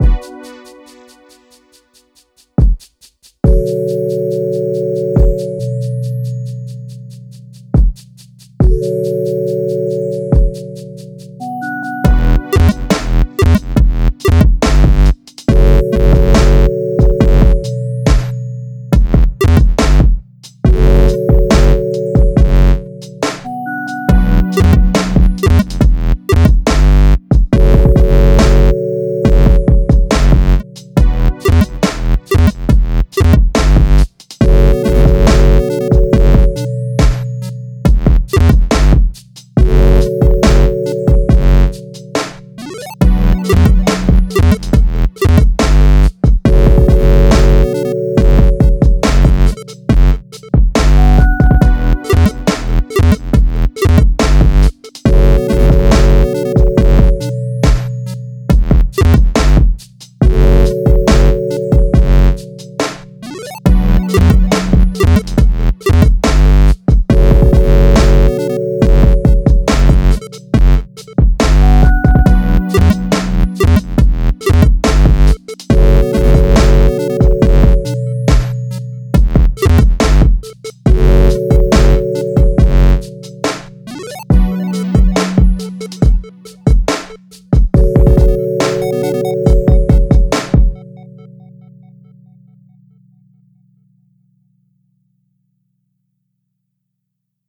Urban nights atmosphere over slow beat.